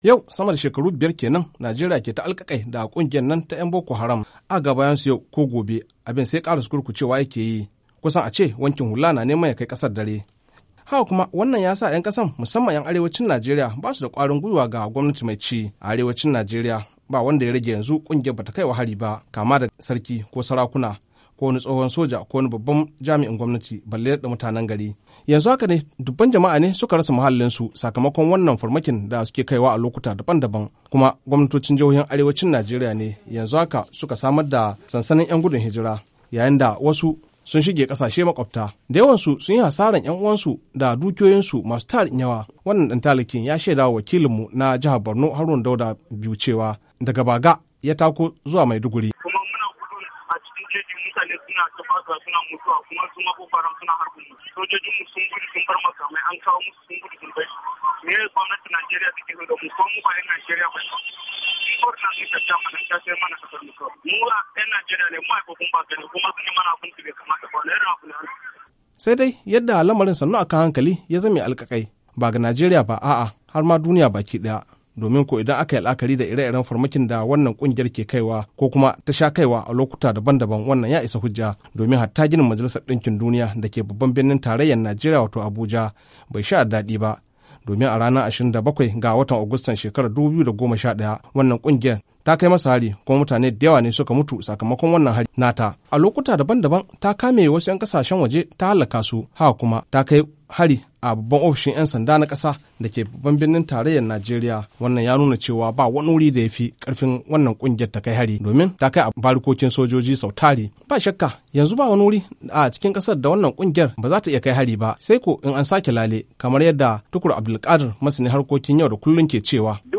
Rahoto Na Musamman Akan Baga: Babi na Biyu